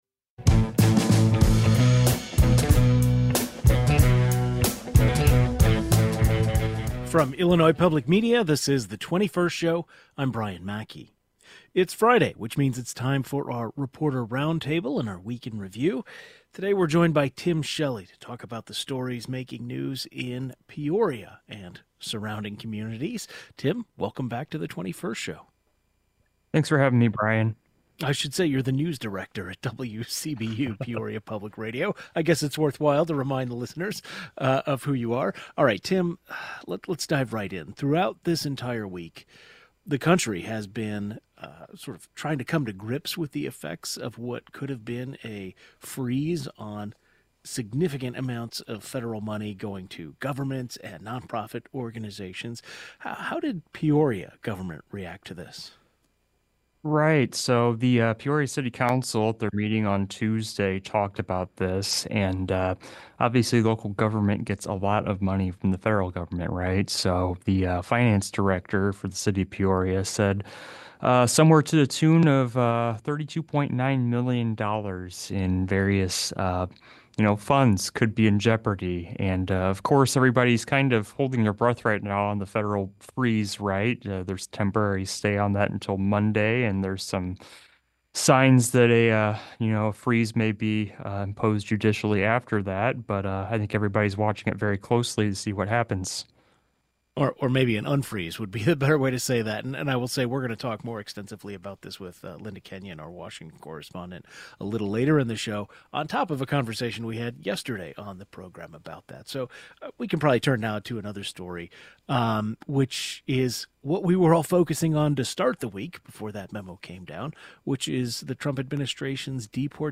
Our Friday reporter roundtable began with stories making headlines in the Peoria area, including how city officials reacted to a potential federal funding freeze, a recent mayoral debate sponsored by WCBU, and the naming rights of the Peoria Civic Center.